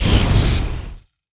explo.wav